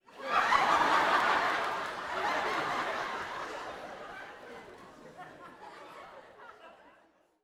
Audience Laughing-08.wav